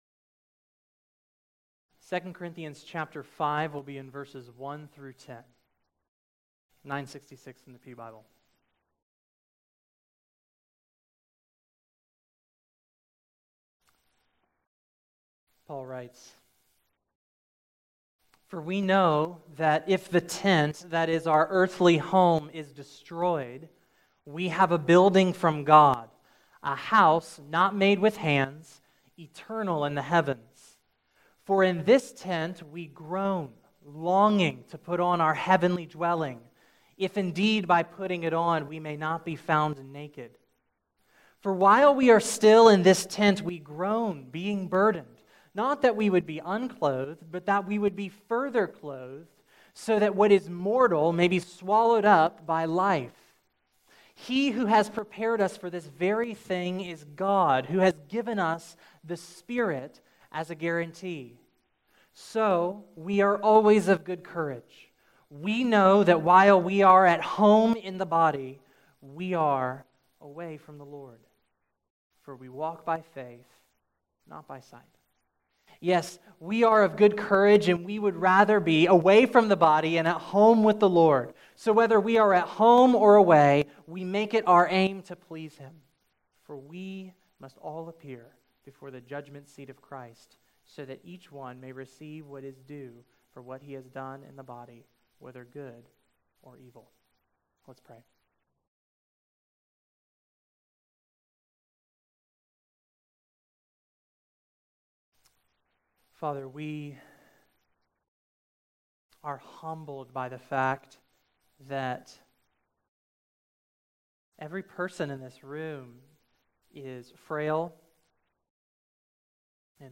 March 5, 2017 Morning Worship | Vine Street Baptist Church